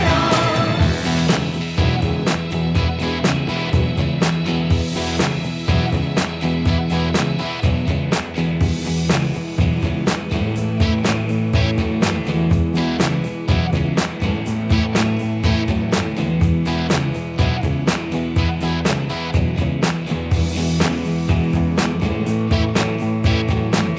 No Guitar Glam Rock 4:24 Buy £1.50